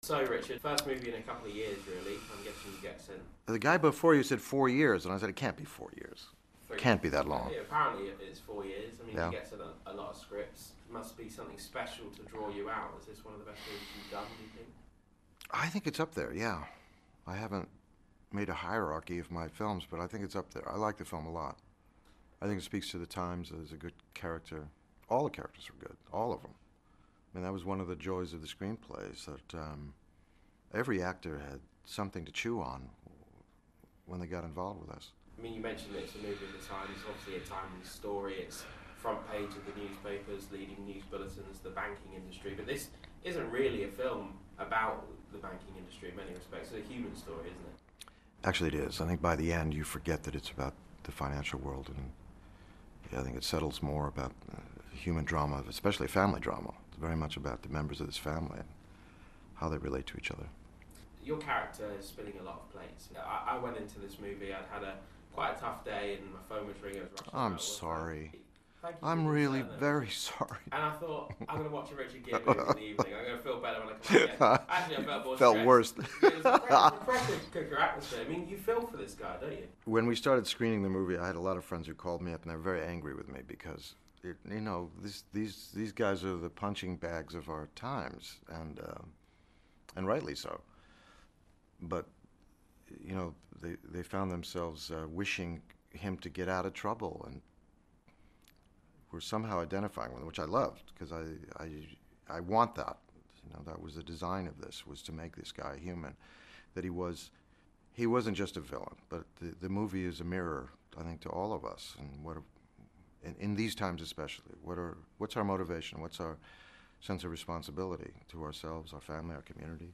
Richard Gere talks to Sky News Radio